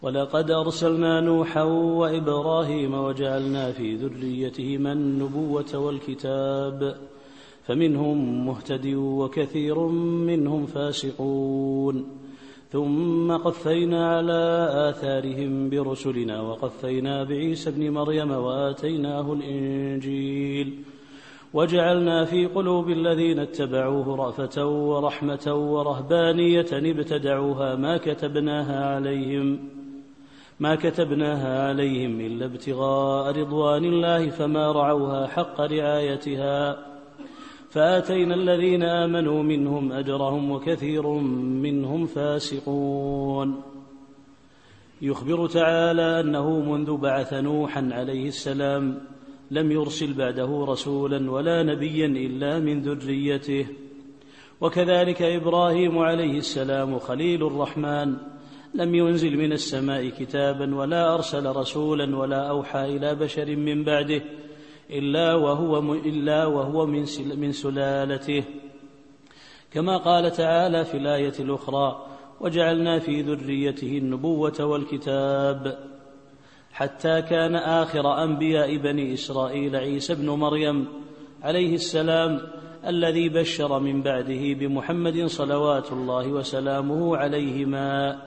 التفسير الصوتي [الحديد / 26]